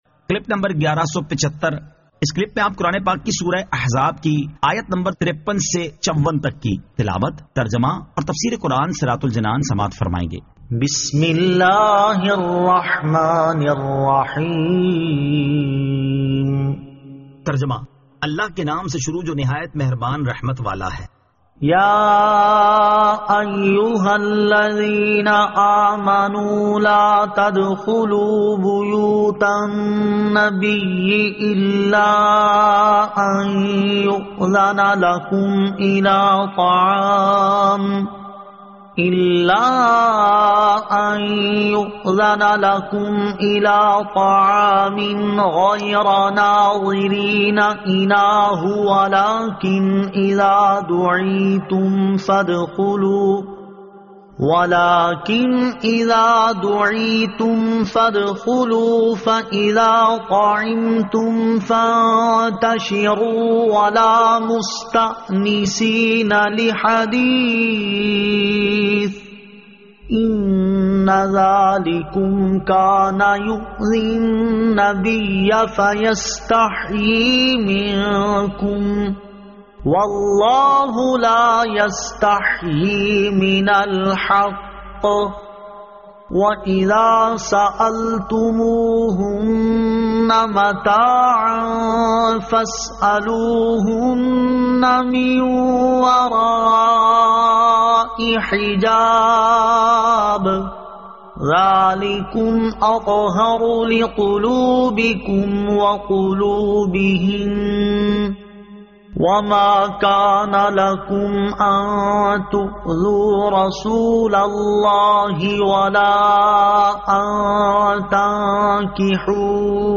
Surah Al-Ahzab 53 To 54 Tilawat , Tarjama , Tafseer
2023 MP3 MP4 MP4 Share سُوَّرۃُ الٗاحٗزَاب آیت 53 تا 54 تلاوت ، ترجمہ ، تفسیر ۔